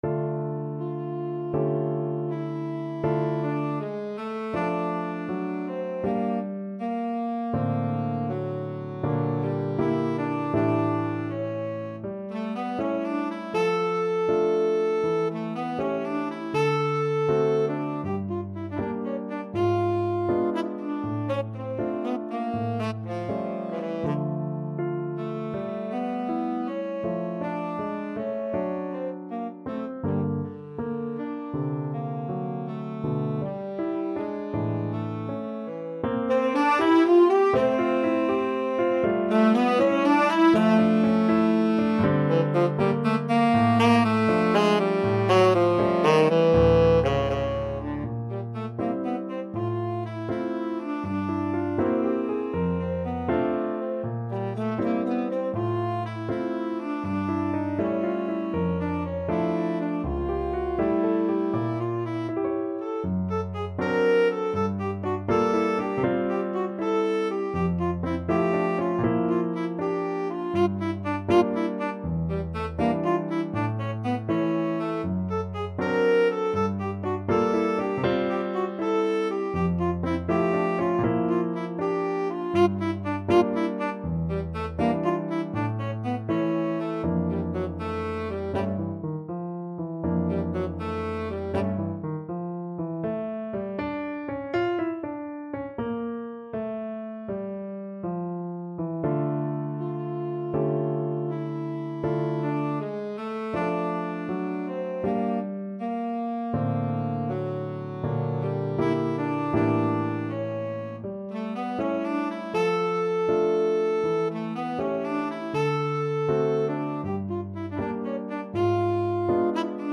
Tenor Saxophone
4/4 (View more 4/4 Music)
D minor (Sounding Pitch) E minor (Tenor Saxophone in Bb) (View more D minor Music for Tenor Saxophone )
Andante doloroso e molto cantabile
Classical (View more Classical Tenor Saxophone Music)